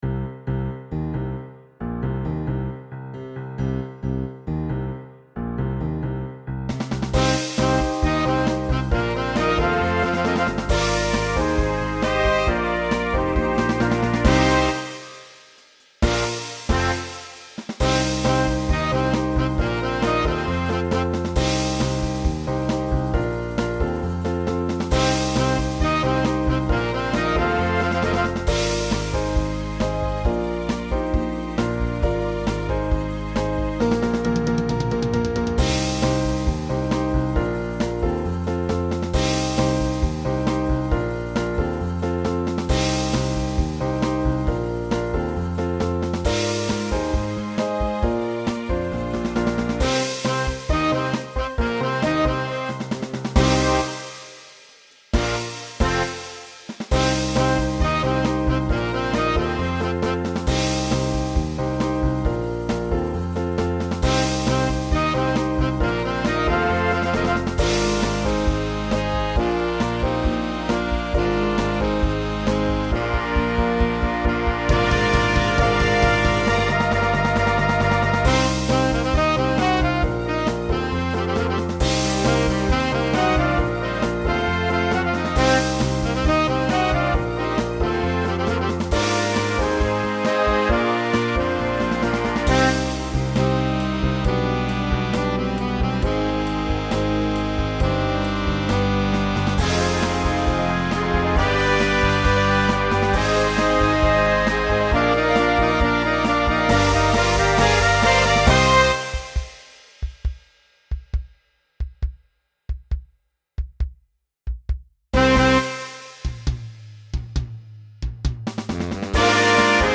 Voicing TTB Instrumental combo Genre Pop/Dance